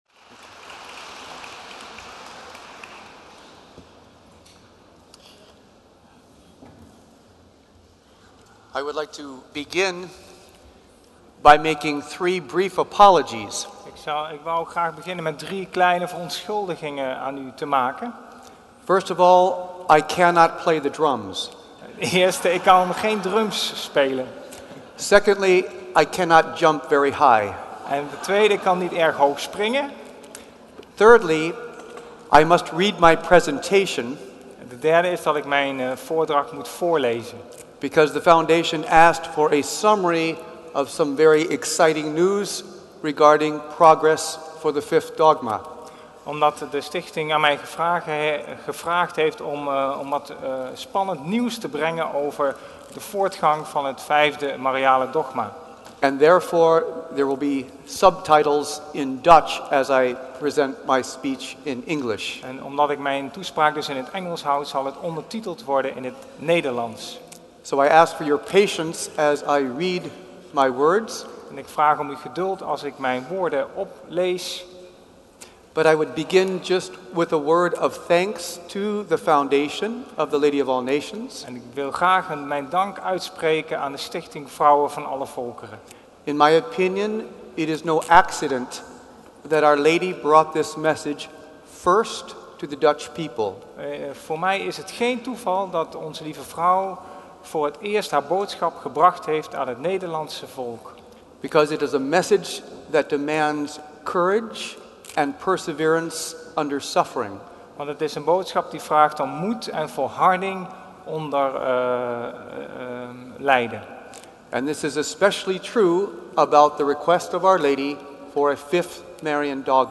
gives a talk at the Our Lady of All Nations Prayer Day in Amsterdam on May 22, 2010. He gives a report on the status of the proclamation of the Fifth Marian Dogma and how this relates to the apparition of Our Lady of All